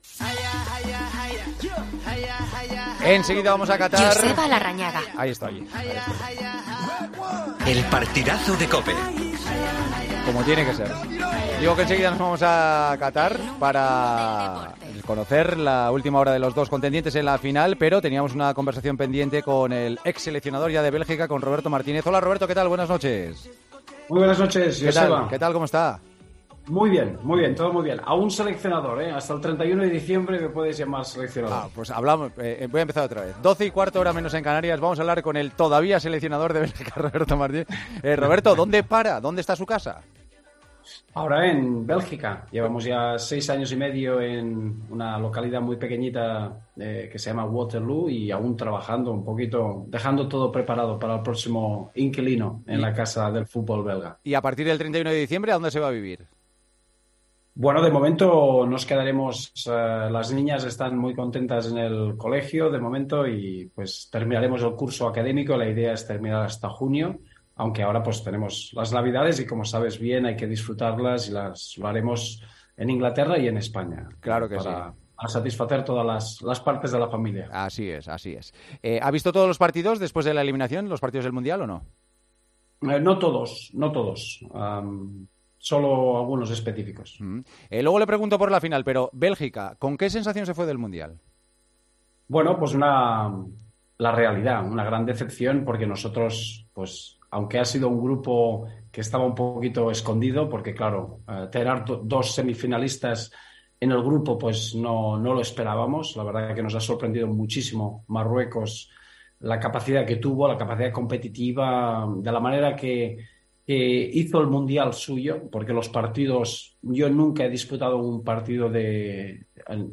ESCUCHA LA ENTREVISTA A ROBERTO MARTÍNEZ, EN EL PARTIDAZO DE COPE